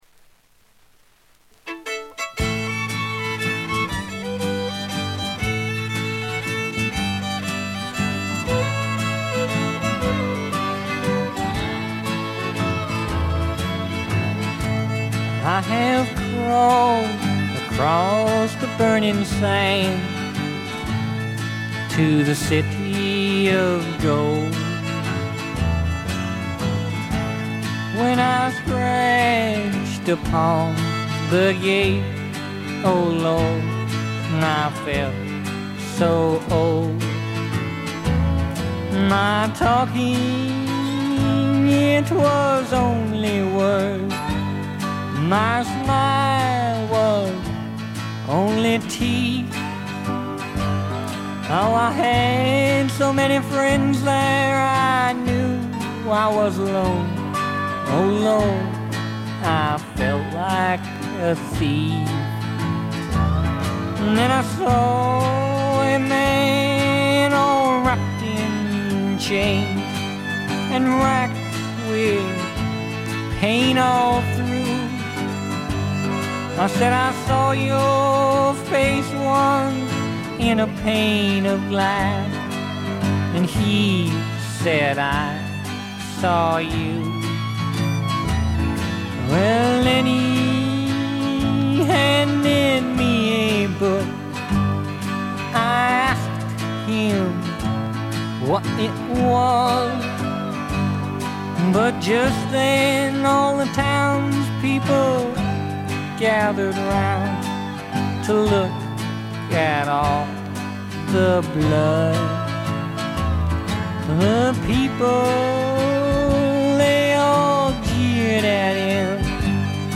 静音部で低いバックグラウンドノイズ。目立つノイズはありません。
試聴曲は現品からの取り込み音源です。